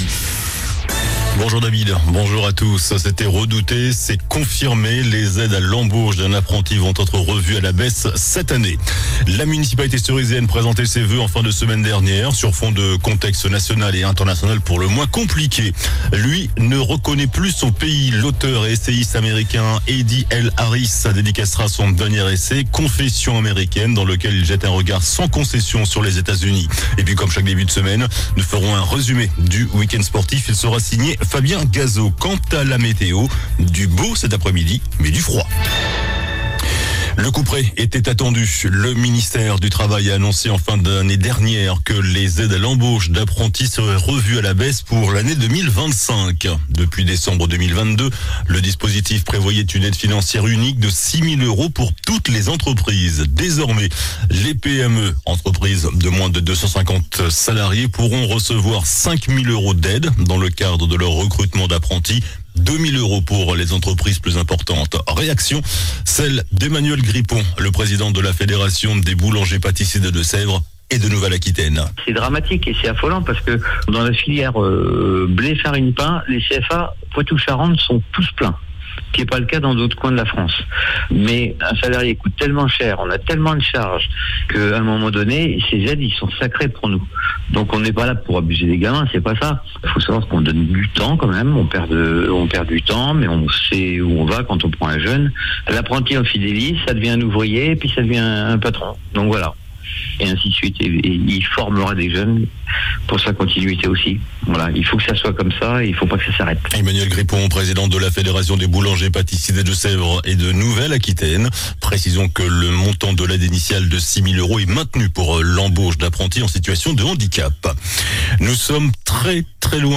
JOURNAL DU LUNDI 13 JANVIER ( MIDI )